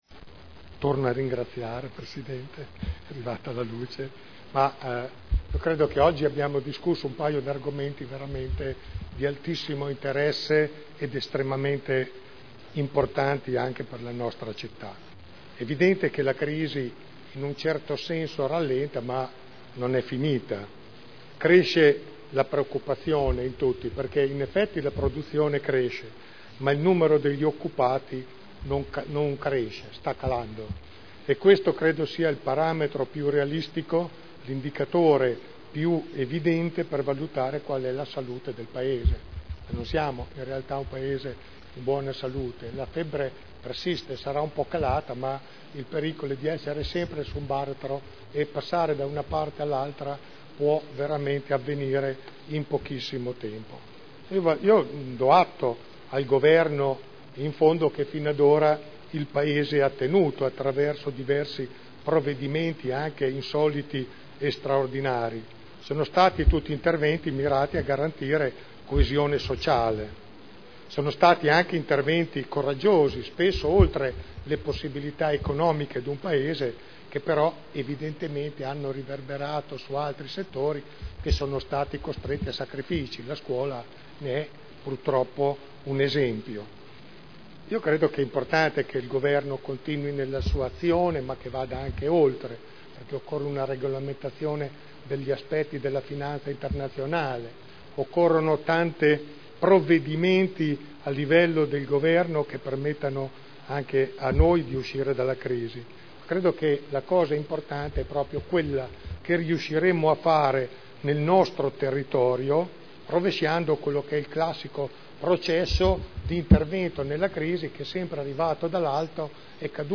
Gian Carlo Pellacani — Sito Audio Consiglio Comunale